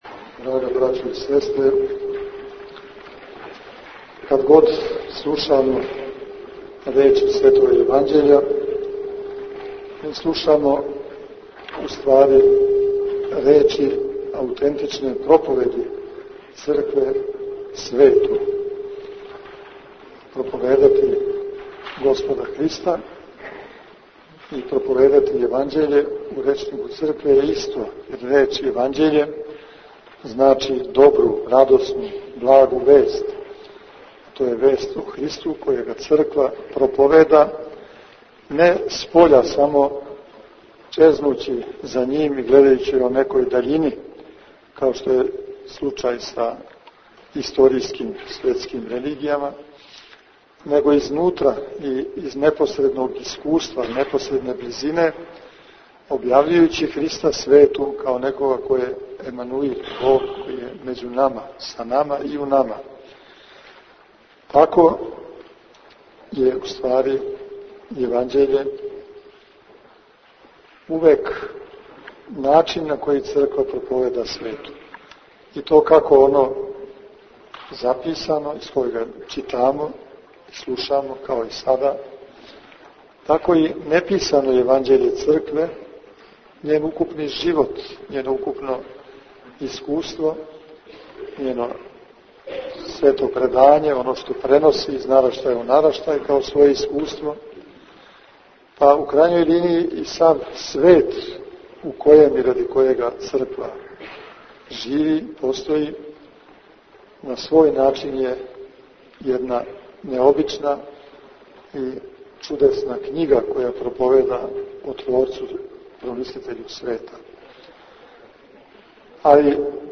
Беседа Епископа Иринеја